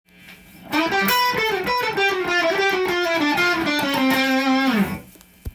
細かい音符が多くなる印象です。